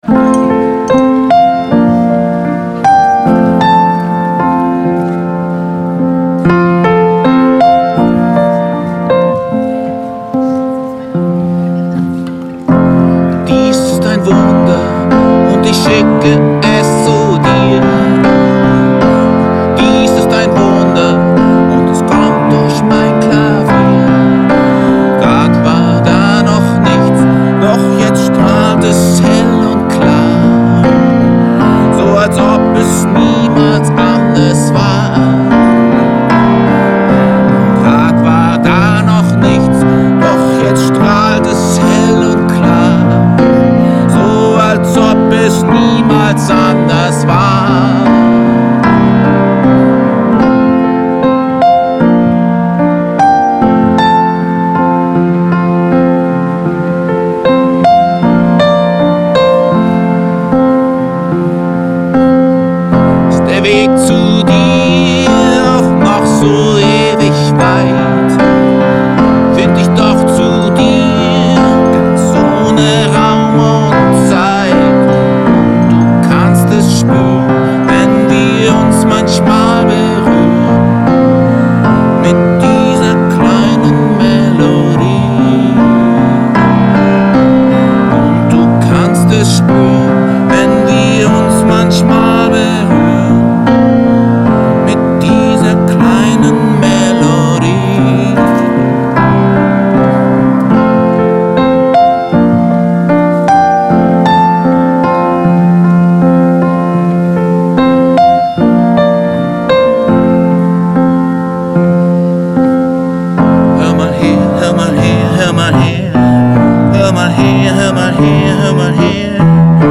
Waldpflanzengarten: